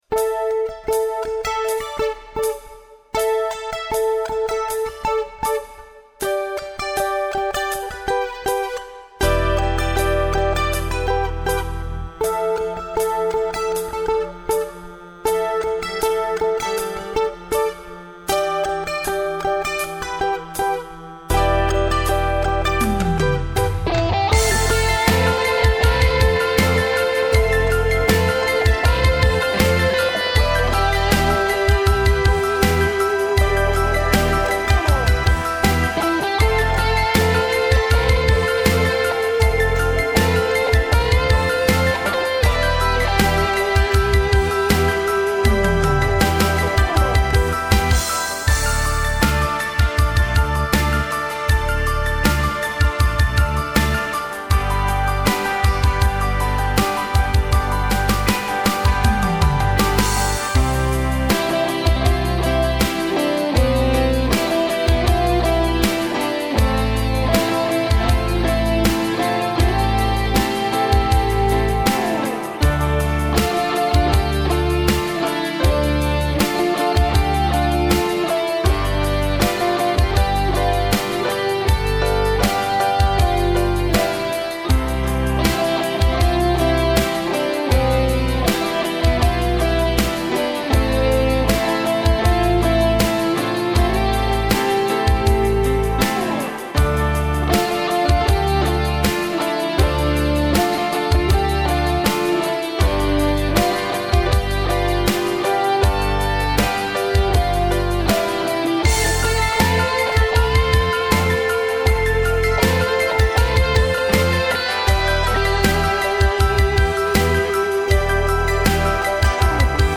Instrumentals